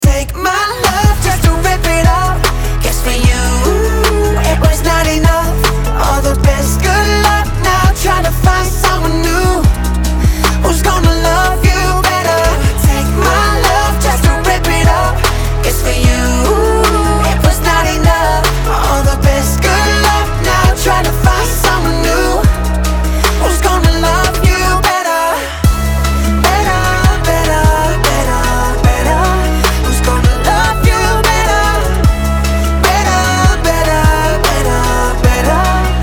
громкие
Dance Pop